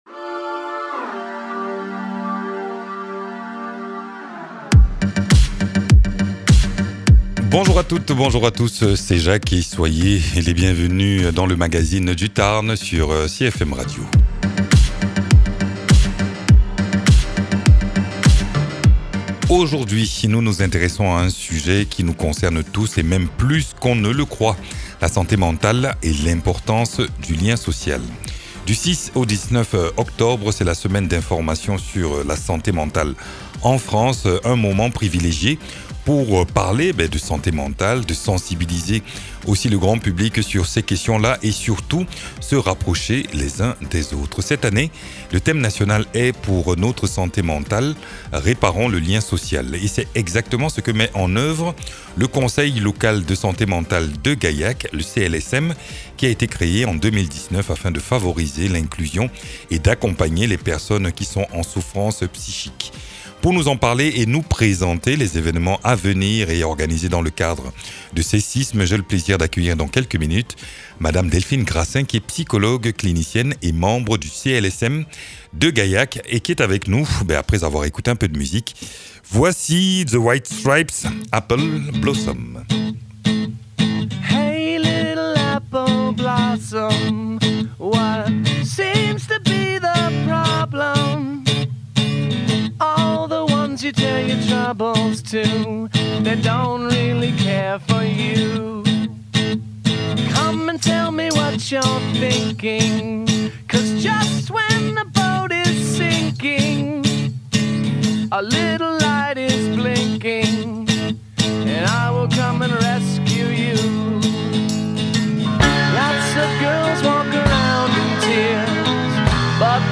Nous découvrons comment cette instance agit au quotidien pour favoriser le bien-être psychique, faciliter l’accès aux soins et renforcer la coopération entre acteurs locaux. La discussion explore également les actions concrètes menées sur le territoire pour lutter contre la stigmatisation et soutenir les personnes concernées.